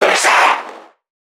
NPC_Creatures_Vocalisations_Infected [106].wav